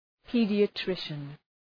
Προφορά
{,pi:dıə’trıʃən} (Ουσιαστικό) ● παιδίατρος